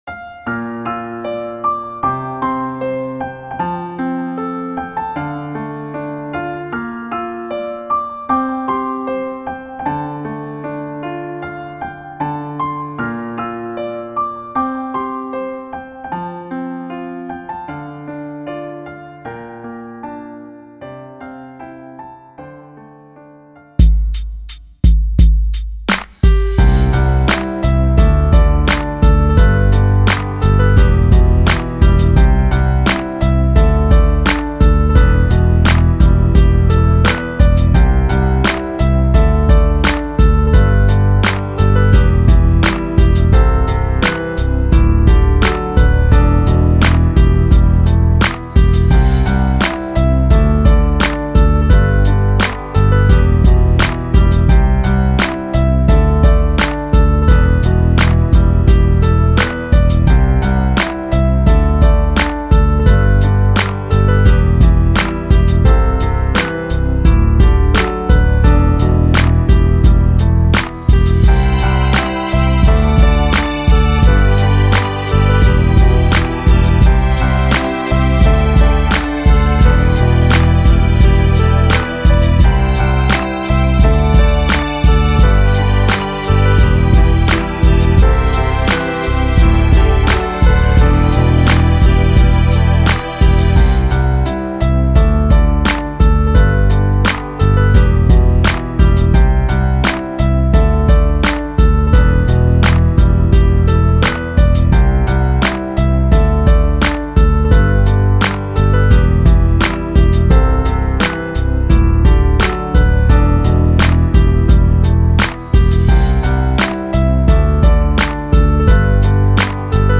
纯音乐 很不错的纯背景音乐.mp3